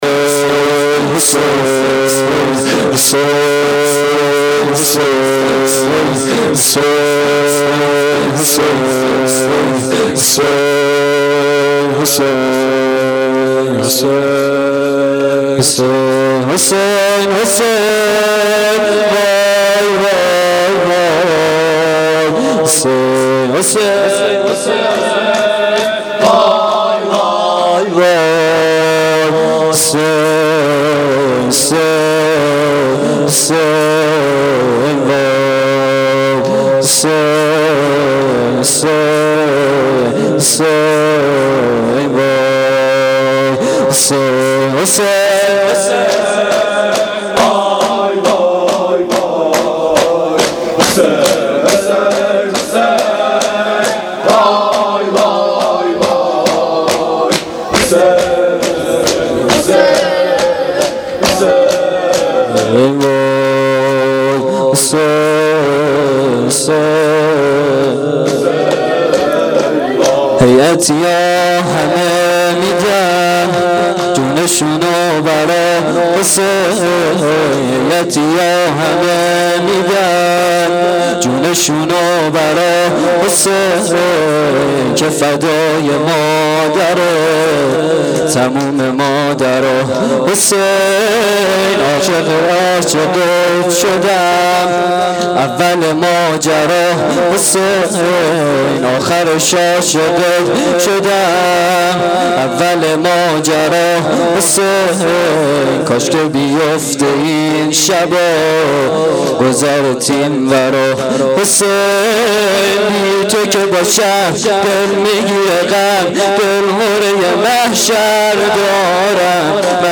شور شب دوم محرم الحرام 1395